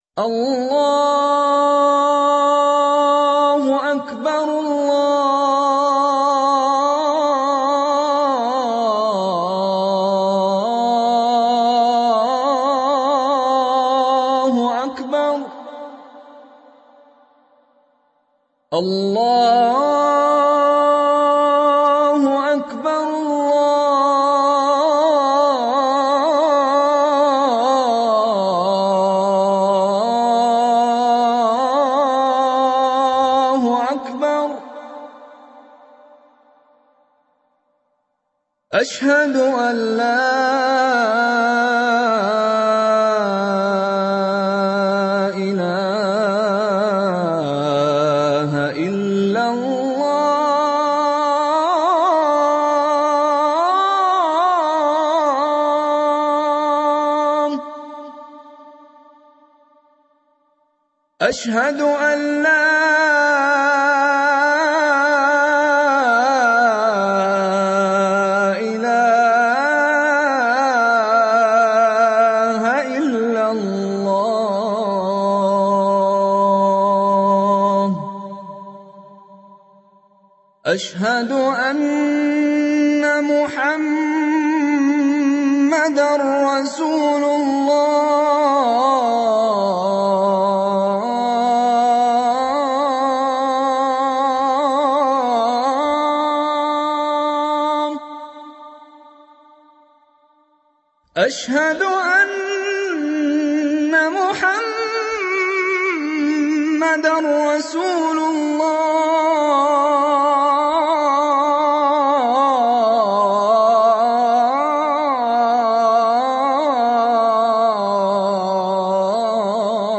أناشيد ونغمات
عنوان المادة الأذان1 _الشيخ :-مشاري بن راشد العفاسي